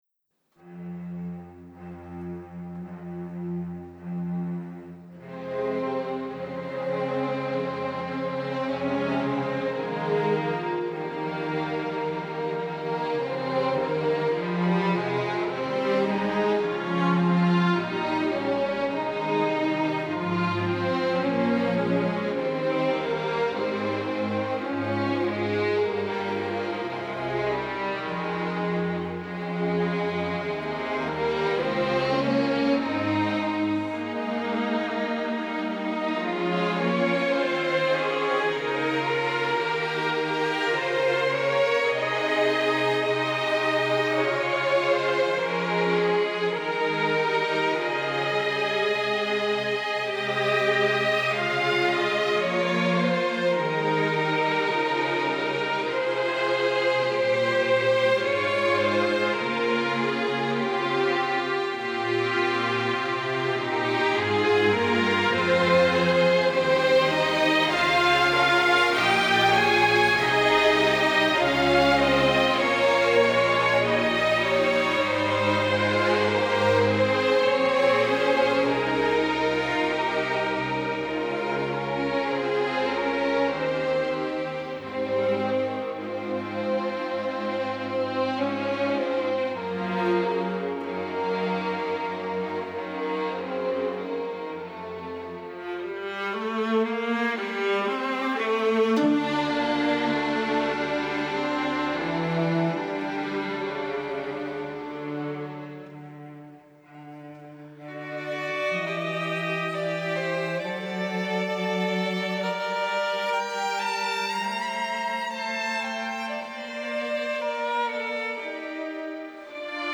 Prelude+for+String+Orchestra.mp3